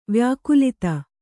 ♪ vyākulita